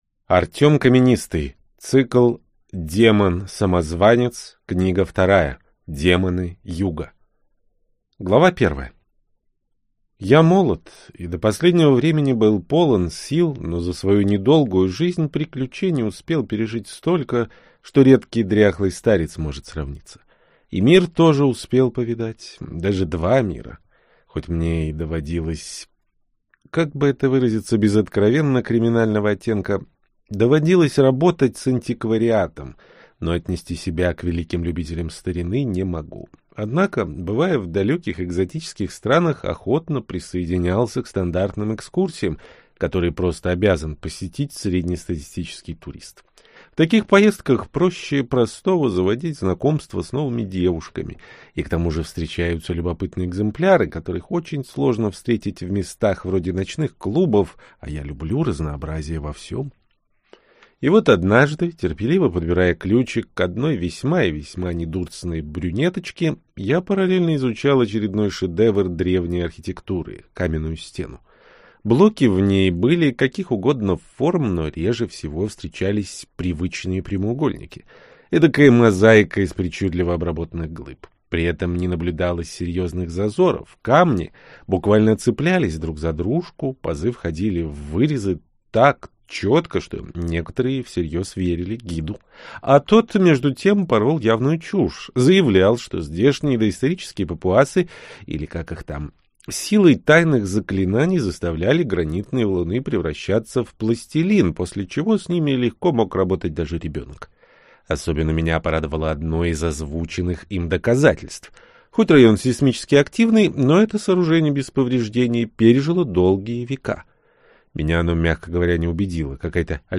Аудиокнига Демоны Юга - купить, скачать и слушать онлайн | КнигоПоиск
Аудиокнига «Демоны Юга» в интернет-магазине КнигоПоиск ✅ Фэнтези в аудиоформате ✅ Скачать Демоны Юга в mp3 или слушать онлайн